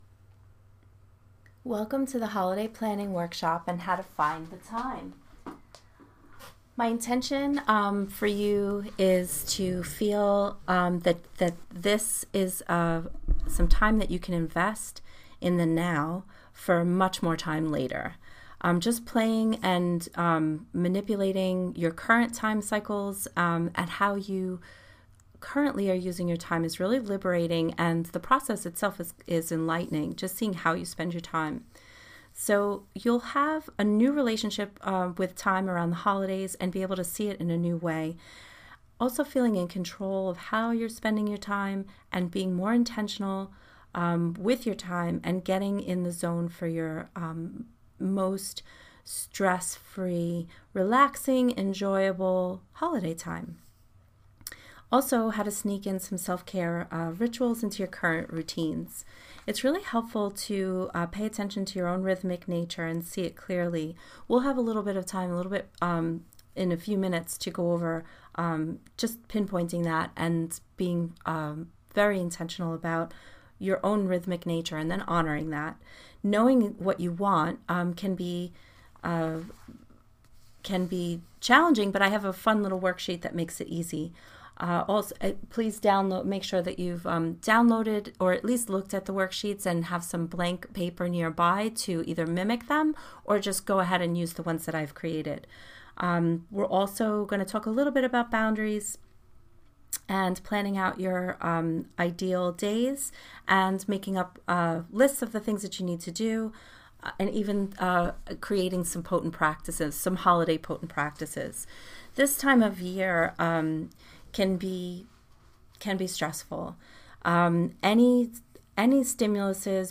Interactive workshop